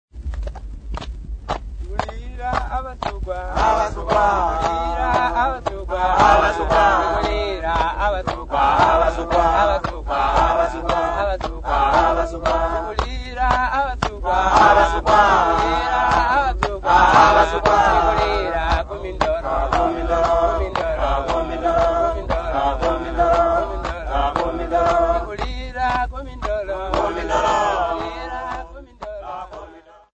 Sukwa men and women at Mindolo Compound
Folk Music
Field recordings
sound recording-musical
Indigenous music